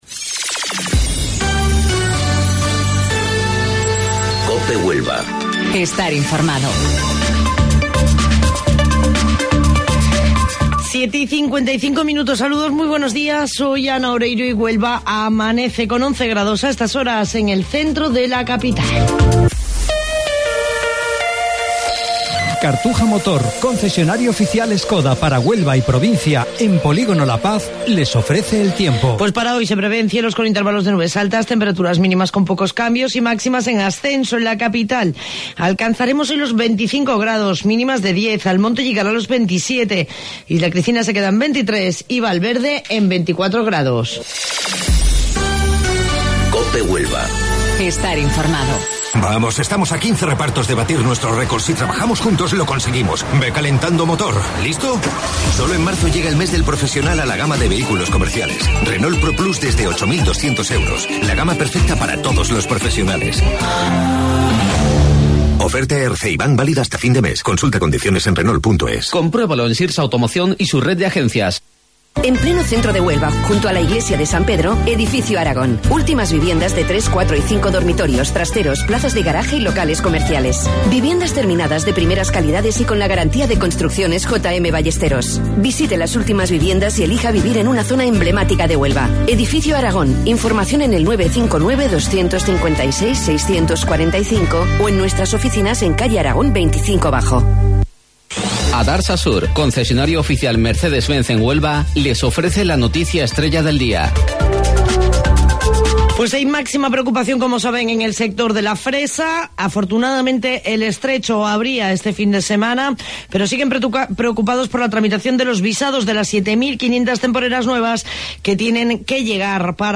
AUDIO: Informativo Local 07:55 del 25 de Marzo